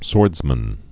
(sôrdzmən)